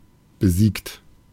Ääntäminen
Ääntäminen US UK : IPA : /dɪˈfiːtəd/ Haettu sana löytyi näillä lähdekielillä: englanti Käännös Ääninäyte Adjektiivit 1. besiegt 2. unterlegen Defeated on sanan defeat partisiipin perfekti.